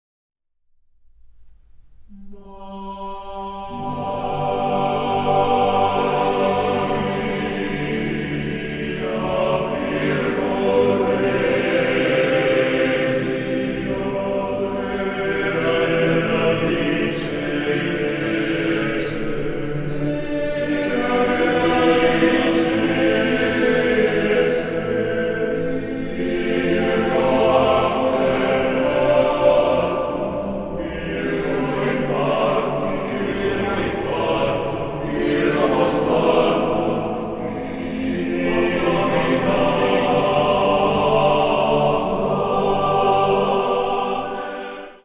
for two cellos and male choir